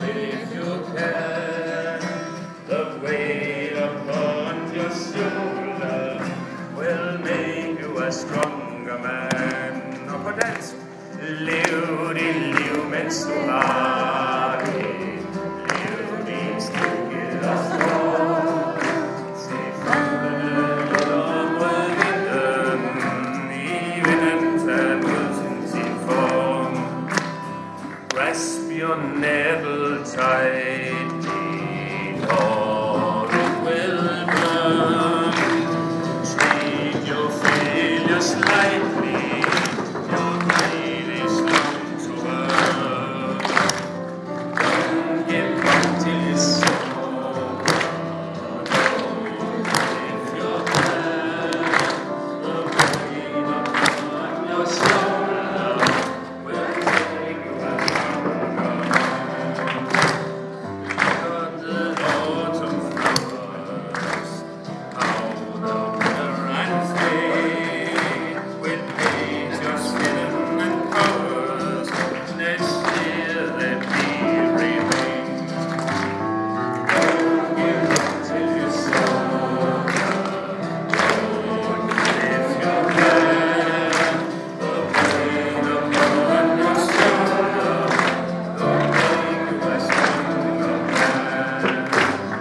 playing and singing w bishop in Aalborg.